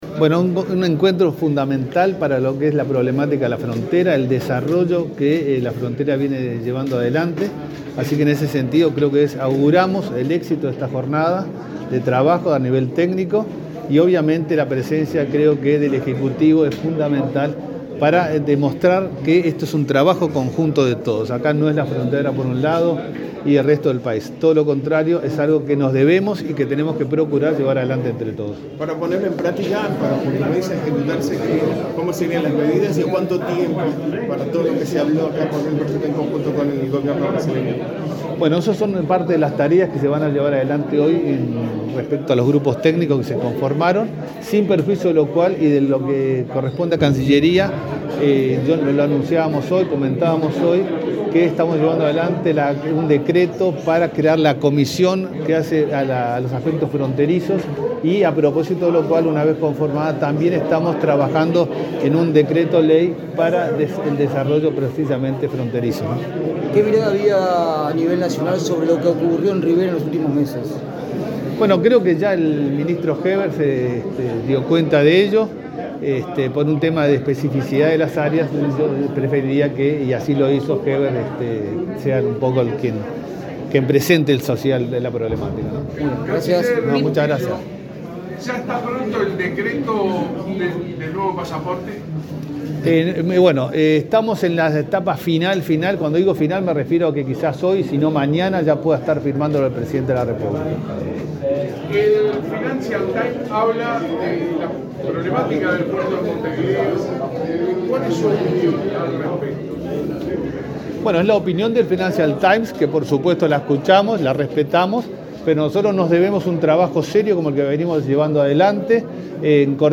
Declaraciones del canciller, Francisco Bustillo, a la prensa
Declaraciones del canciller, Francisco Bustillo, a la prensa 30/08/2022 Compartir Facebook X Copiar enlace WhatsApp LinkedIn El ministro del Interior, Luis Alberto Heber; el canciller, Francisco Bustillo, y el ministro de Defensa Nacional, Javier García, participaron en Rivera en un encuentro binacional entre Uruguay y Brasil sobre seguridad pública. Luego, Bustillo dialogó con la prensa.